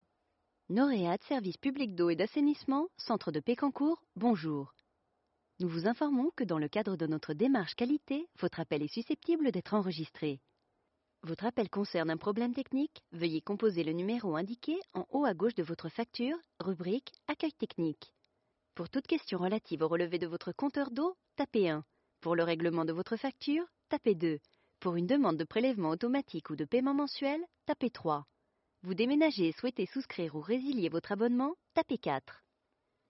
FR EU MN IVR 01 IVR/Phone systems Female French (European)